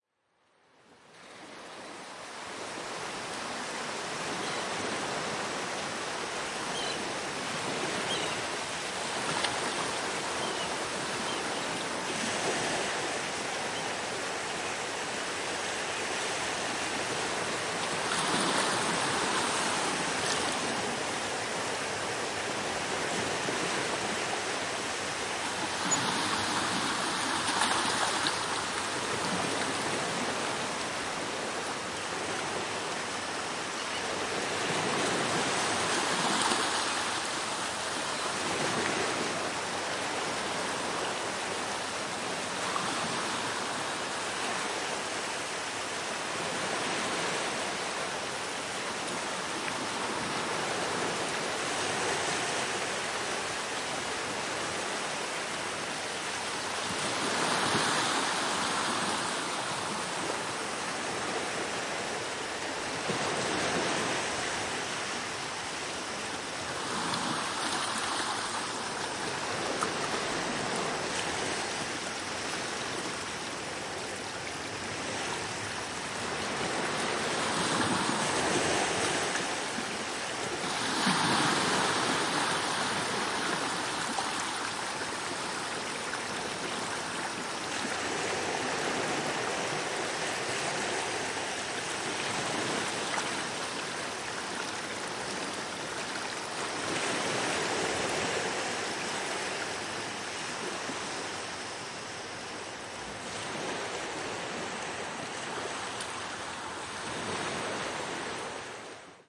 波罗的海声景
Tag: 声景 声音效果 声音设计 波罗海 FX